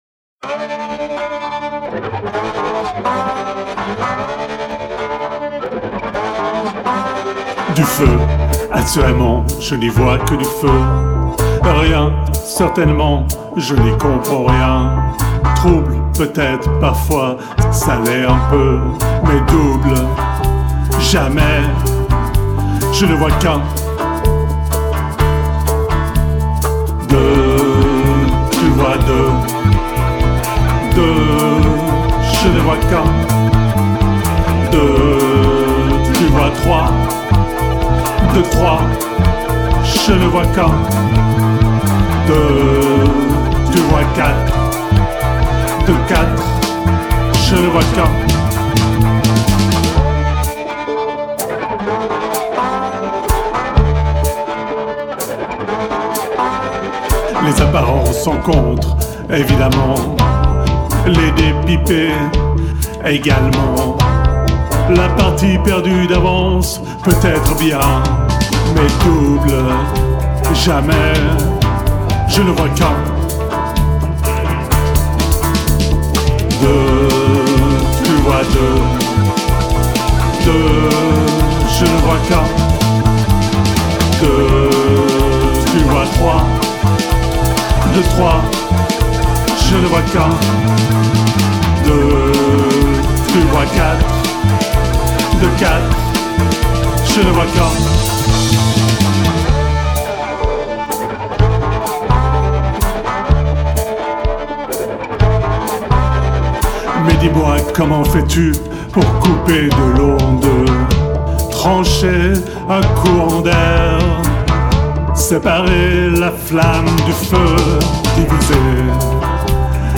guitare, basse, clavier
Studio des Anges, Lausanne